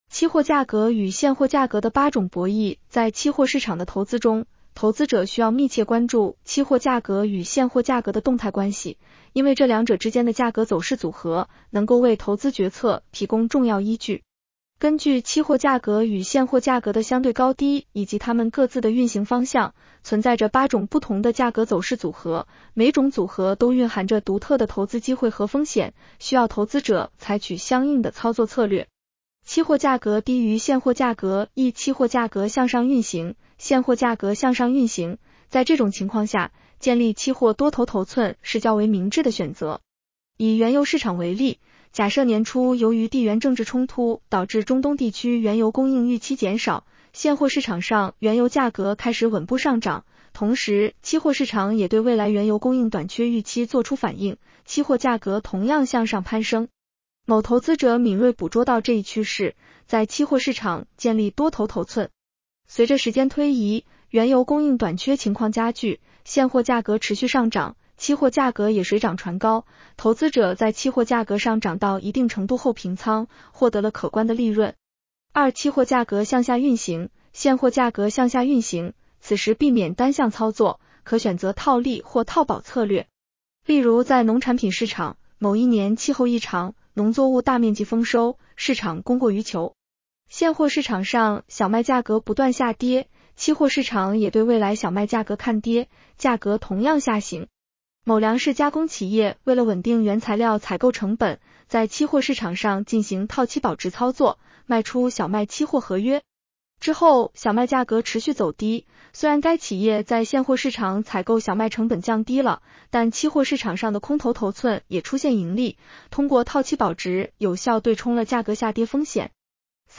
女声普通话版 下载mp3 期货价格与现货价格的8种博弈 在期货市场的投资中，投资者需要密切关注期货价格与现货价格的动态关系，因为这两者之间的价格走势组合，能够为投资决策提供重要依据。